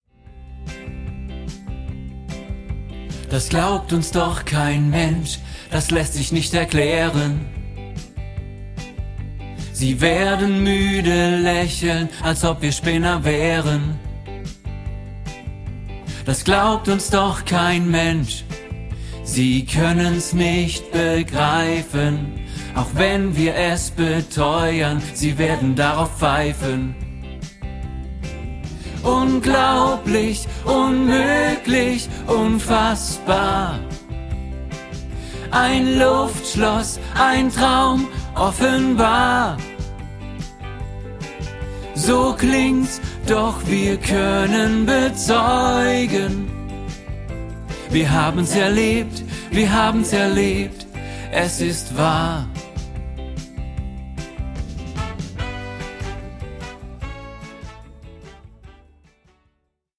Separate Aufnahmen mit hervorgehobenen Tenor-Stimmen.